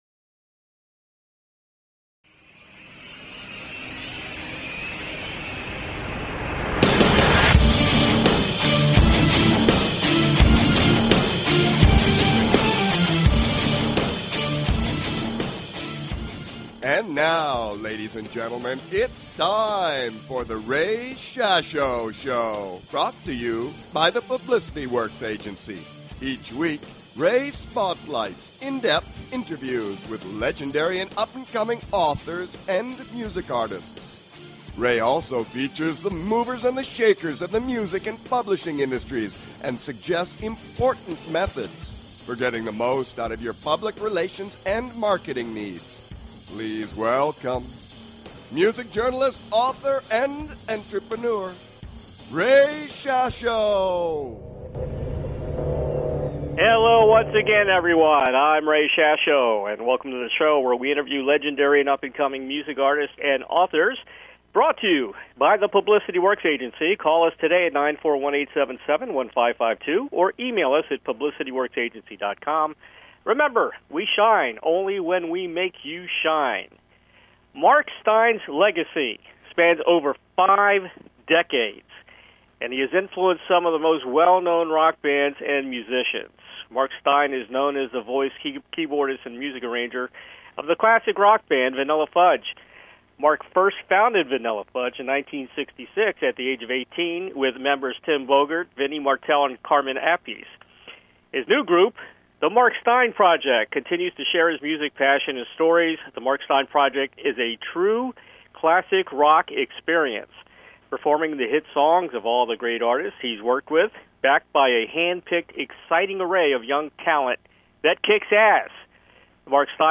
Guest, Mark Stein